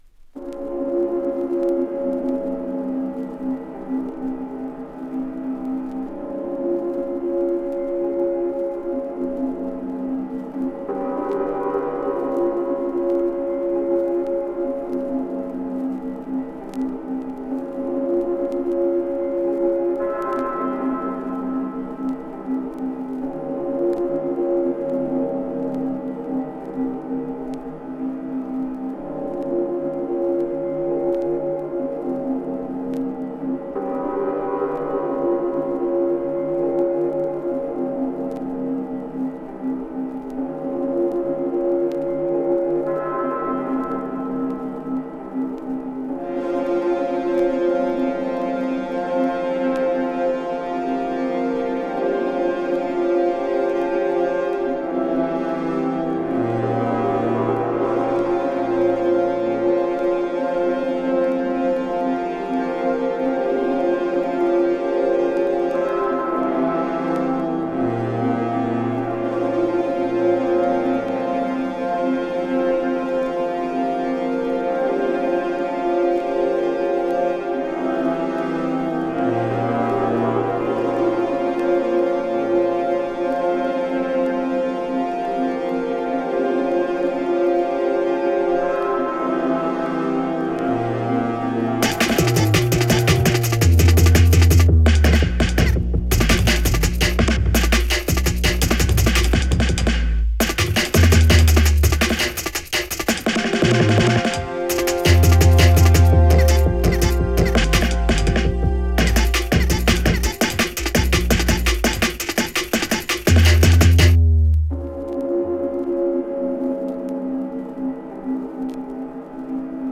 > BASS / DUB STEP / DRUM N' BASS